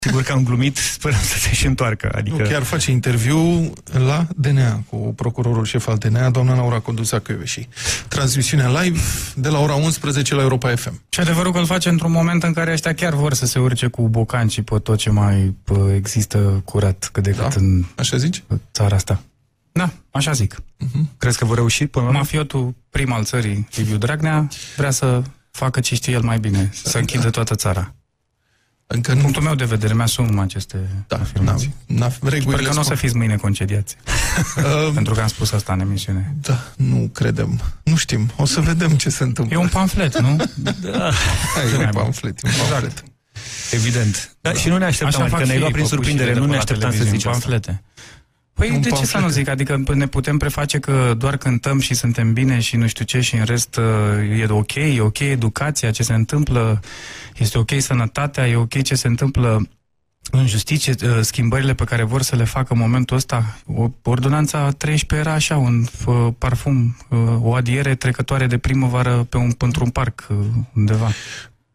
Invitat în emisiunea Deșteptarea, la Europa FM, Tudor Chirilă a vorbit despre probleme sociale și politice de actualitate și despre efectele pe care acestea le au asupra românilor.